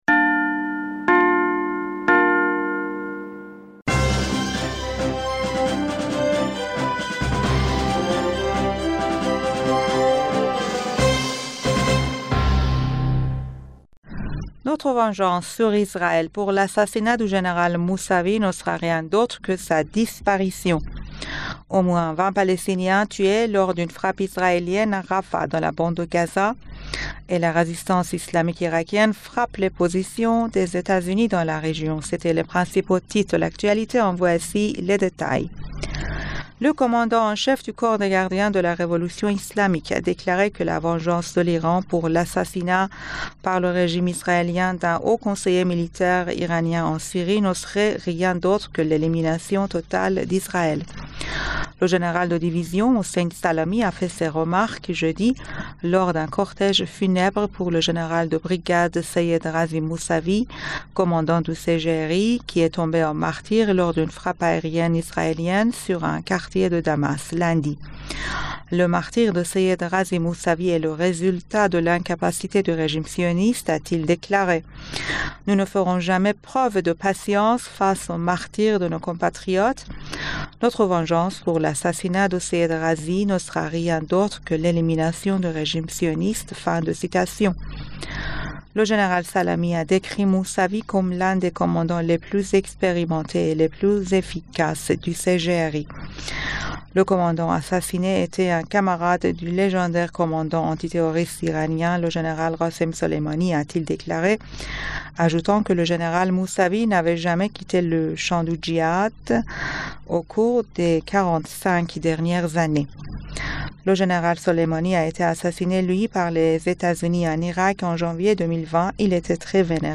Bulletin d'information du 29 Decembre 2023